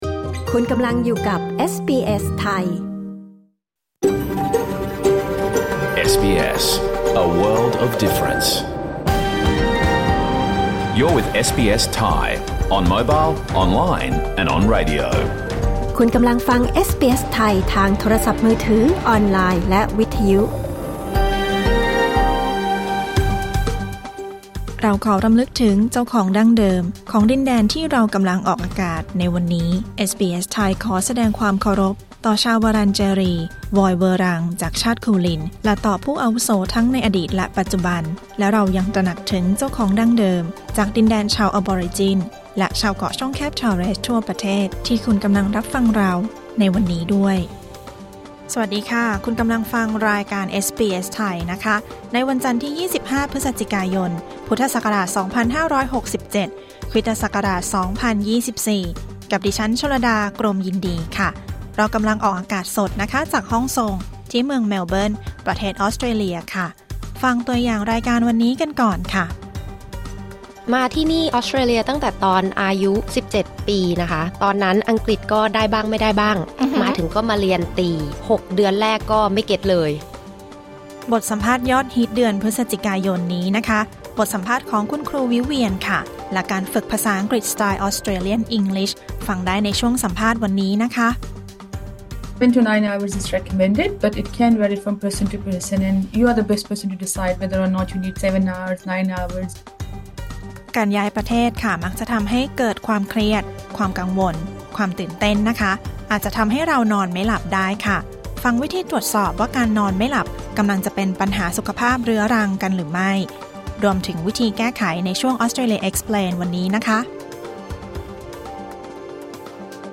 รายการสด 25 พฤศจิกายน 2567